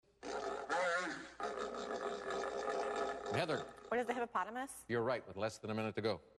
This huge mammal sounds like it's enjoying a bath in an African river